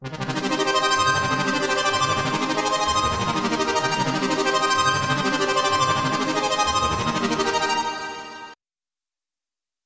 描述：3/4的合成器循环琶音，
Tag: 合成器 琶音 明亮 颤音 美丽